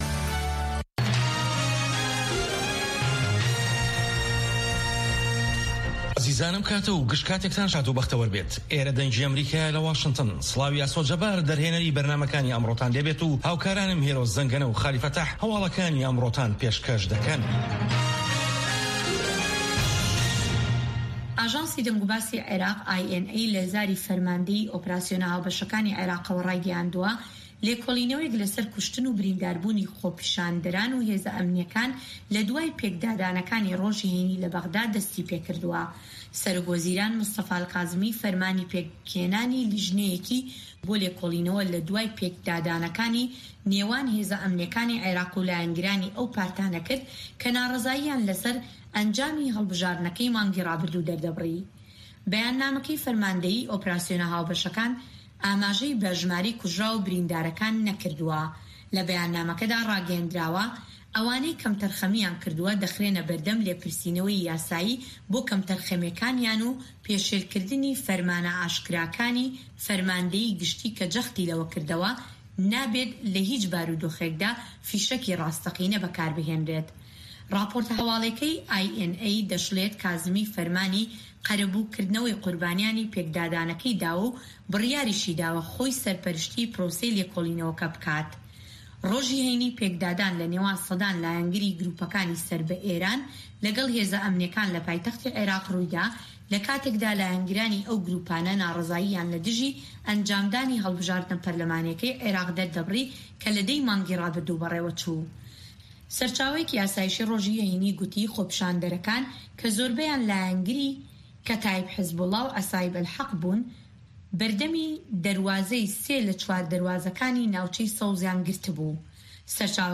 هەواڵەکانی 1 ی پاش نیوەڕۆ